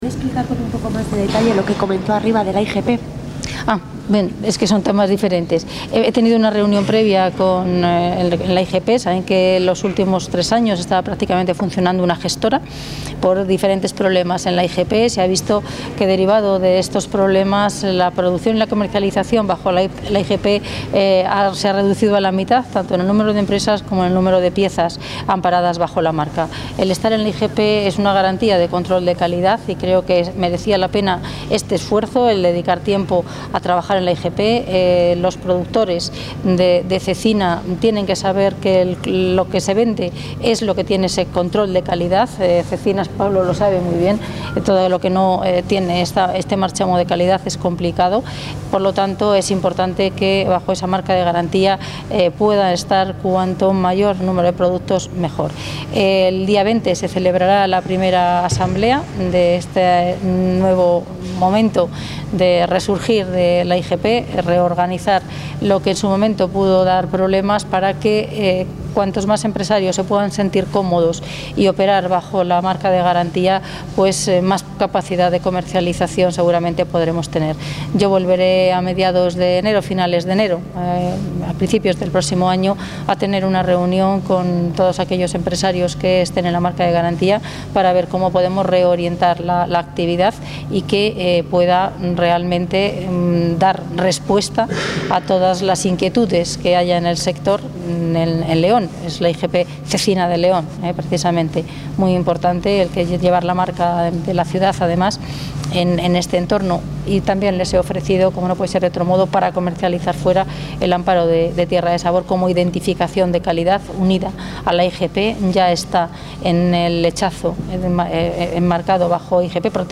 Audio consejera de Agricultura y Ganadería.
Galería Multimedia Audio consejera de Agricultura y Ganadería Audio atención a los medios Visita al Ayuntamiento de Astorga Visita al Ayuntamiento de Astorga Visita a la fábrica Visita a la fábrica Visita a la fábica